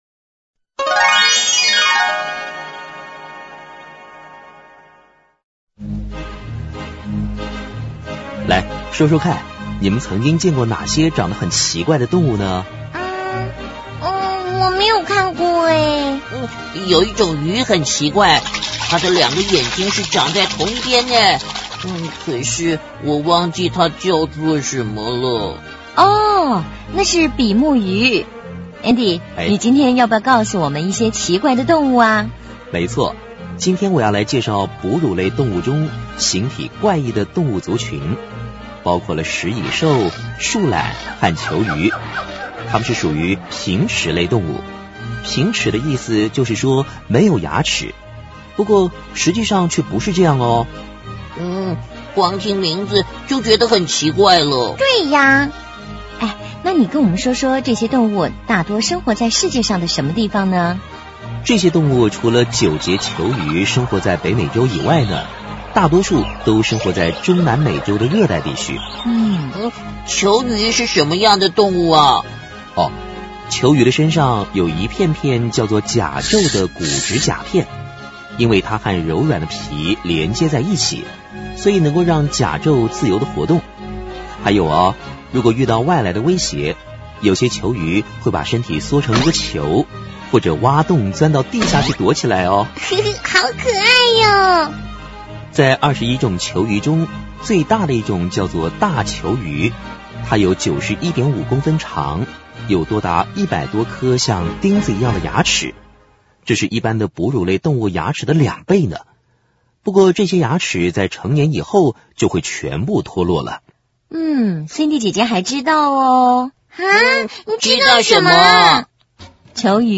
首页>mp3 > 儿童故事 > 食蚁兽、树獭和犰狳